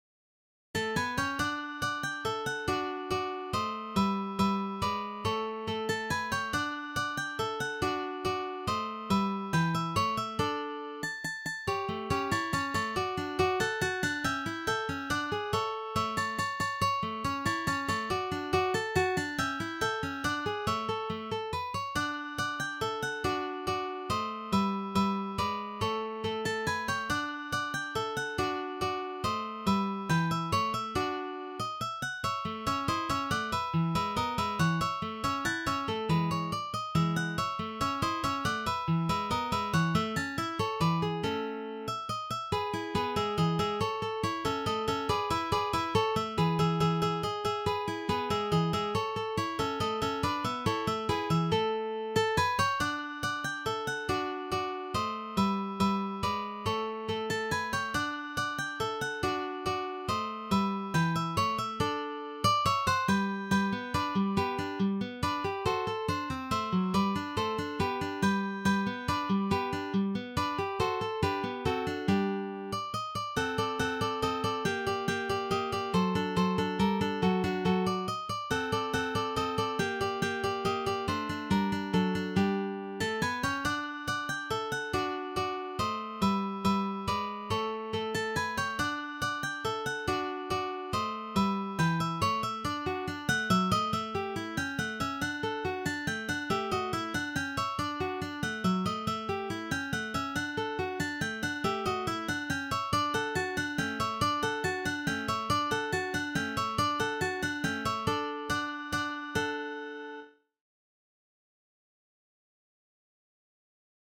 for three guitars